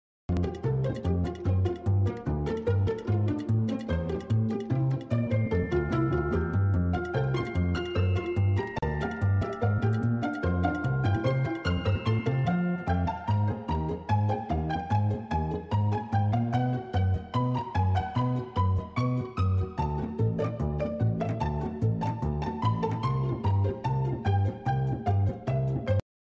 background music sped up